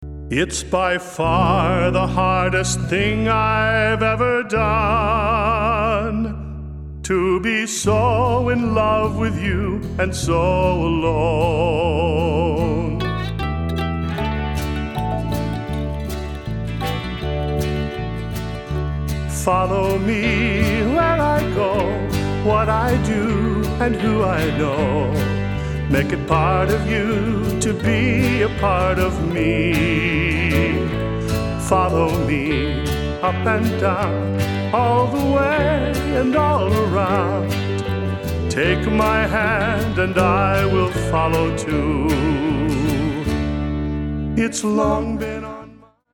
has always wanted to do a Gospel album